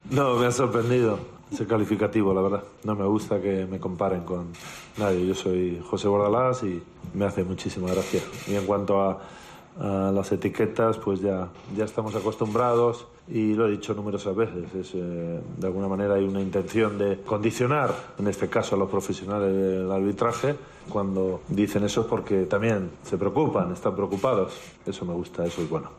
"Es una eliminatoria bonita, la más interesante de estos cruces, sobre todo porque el Getafe está haciendo una magnífica temporada y se enfrenta a un semifinalista de la última Champions, así que será un partido muy atractivo", se sinceró Bordalás en rueda de prensa.